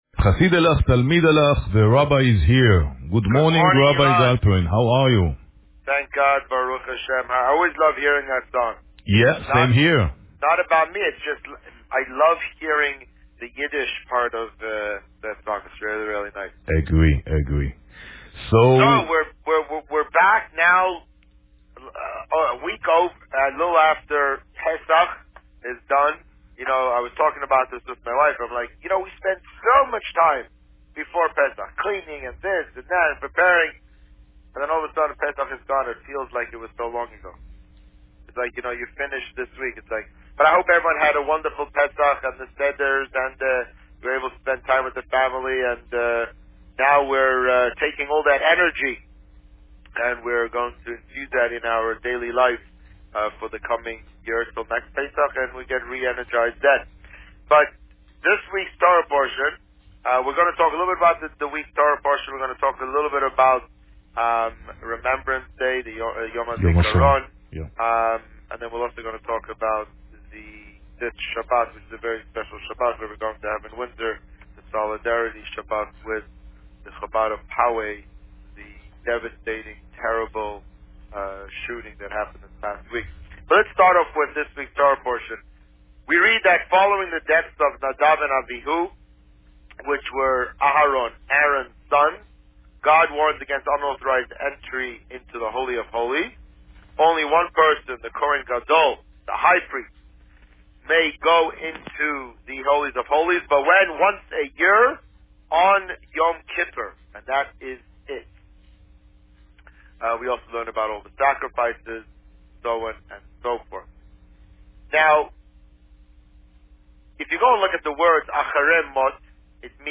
On May 2, 2019, the Rabbi spoke about Parsha Acharei Mot as well as the deadly attack which occured on the last day of Pesach at the Poway Chabad synagogue near San Diego, California. Listen to the interview here.